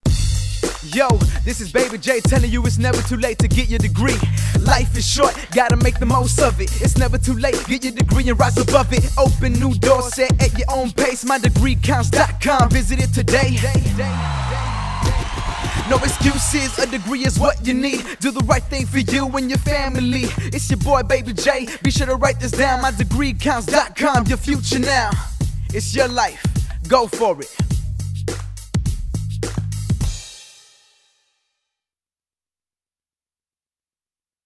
Produced radio spots